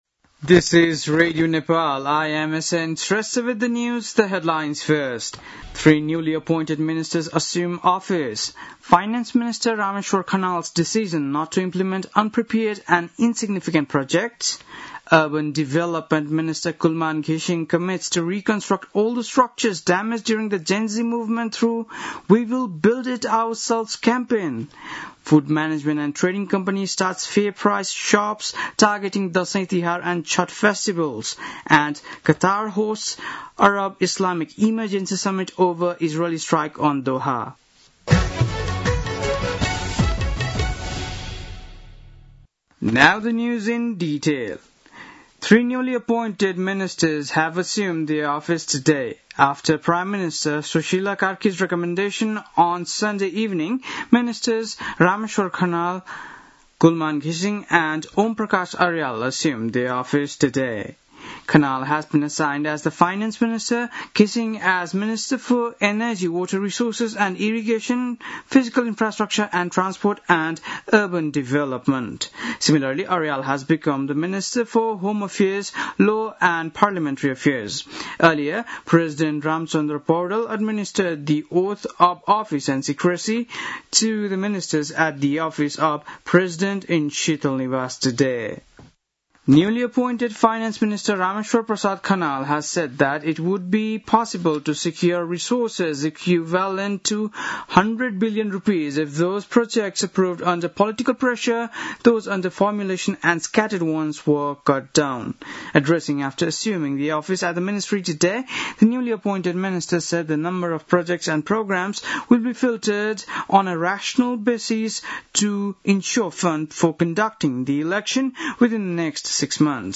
बेलुकी ८ बजेको अङ्ग्रेजी समाचार : ३० भदौ , २०८२